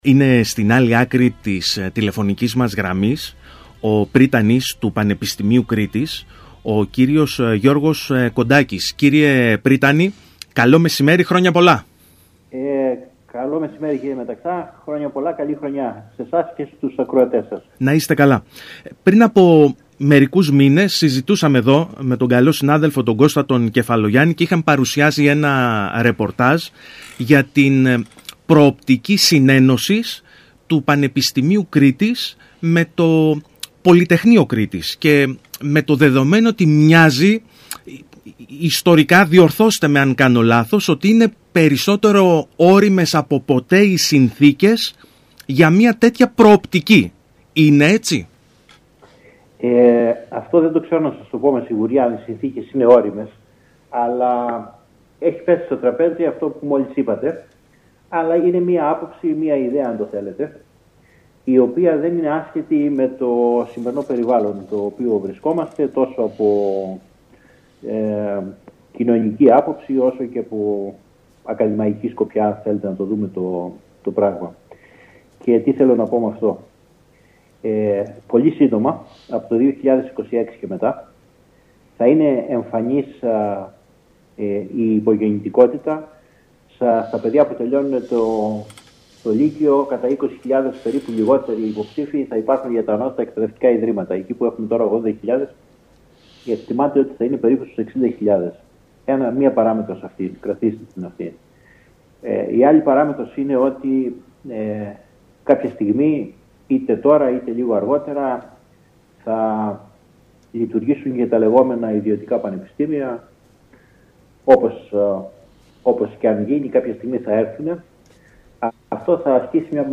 Ακούστε εδώ όσα είπε ο Πρύτανης του Πανεπιστημίου Κρήτης Γιώργος Κοντάκης στον ΣΚΑΙ Κρήτης 92.1: